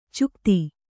♪ cukti